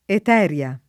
Eteria [ et $ r L a ]